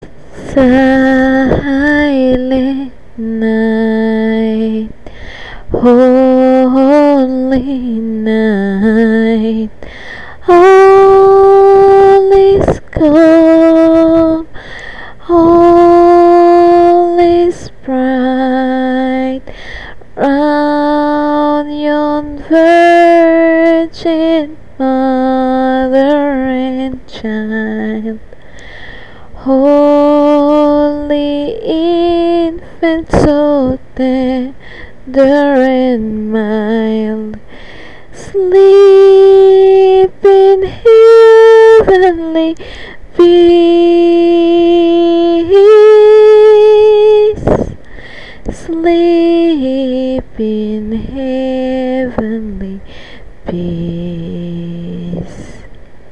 The 2006 Blogger Christmahanukwanzaakah Online Holiday Concert